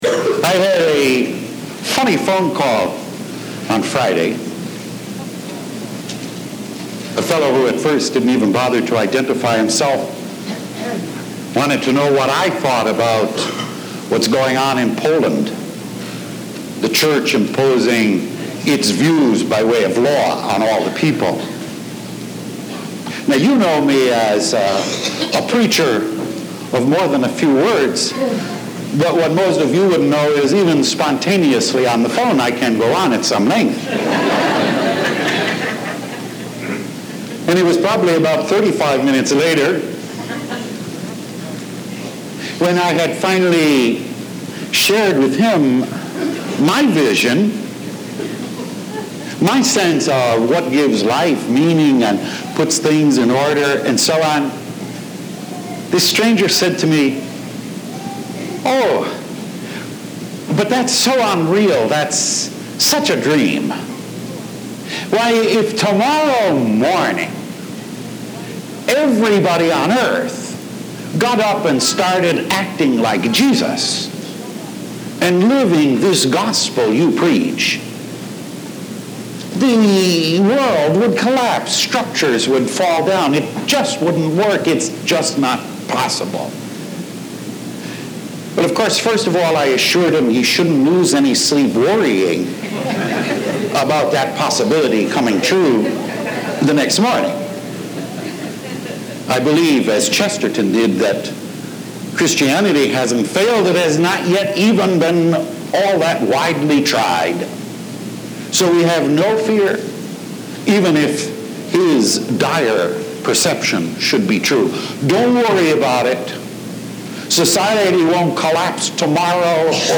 Martin Luther King « Weekly Homilies
Originally delivered on January 15, 1989